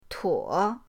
tuo3.mp3